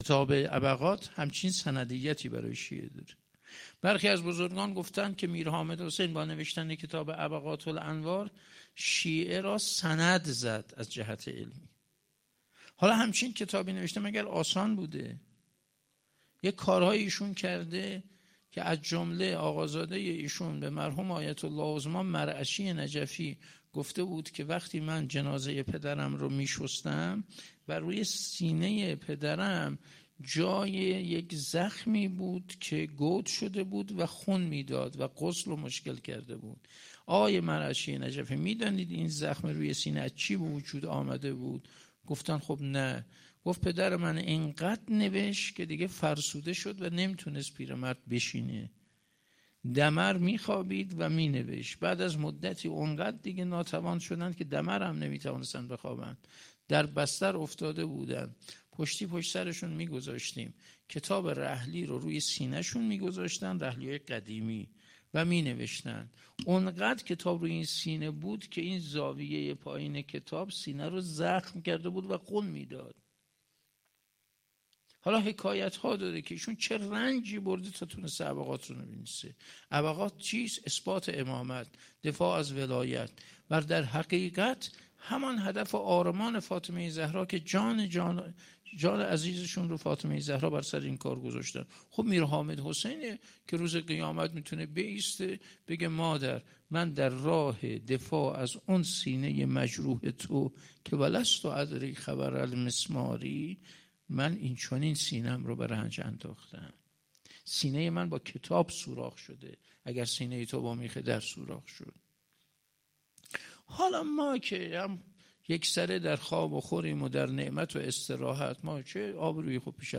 29 دی 97 - سالن همایش های آنلاین - یکی از روش های تقرب به حضرت زهرا سلام الله